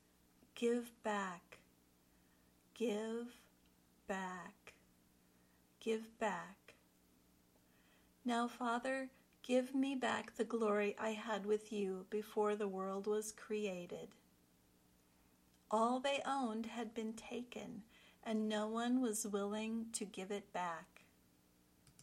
Then, listen to how it is used in the sample sentences.
ɡɪv bæk  (phrasal verb)